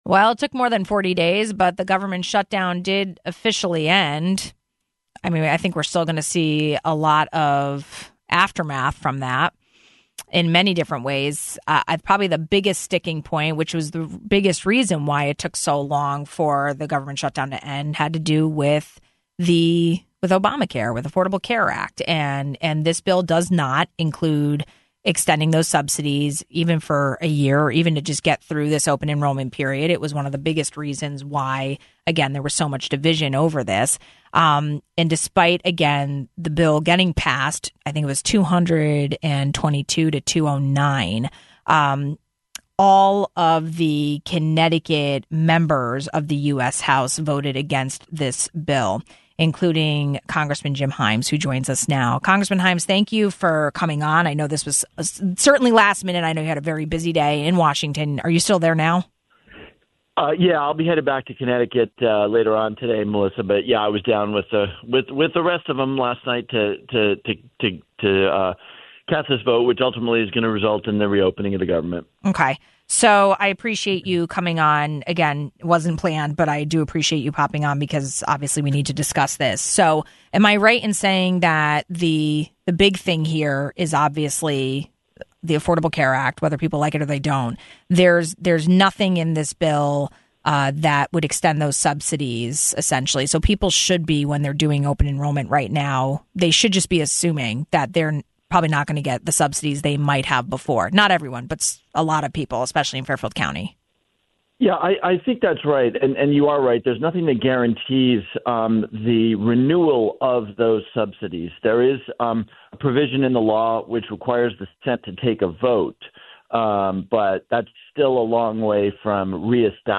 We spoke with Congressman Jim Himes following the news overnight.